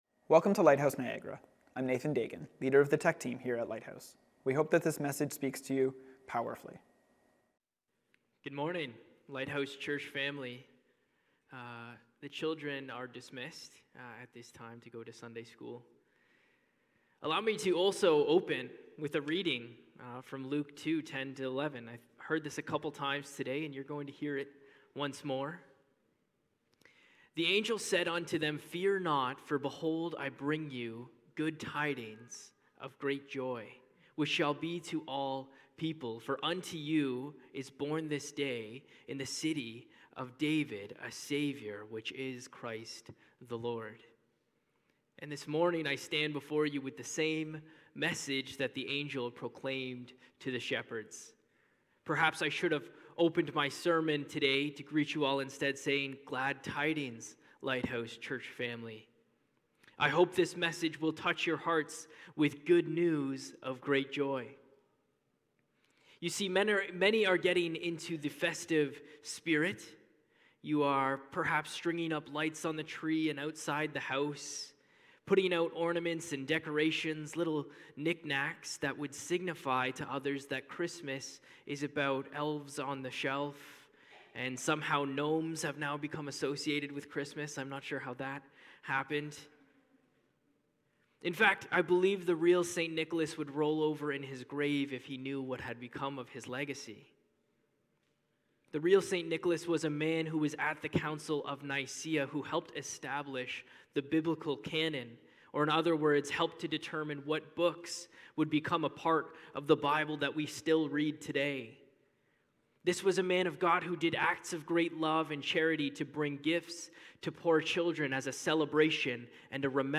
Sunday Morning Service
Lighthouse Niagara Sermons A Star In The East: The Light Of The World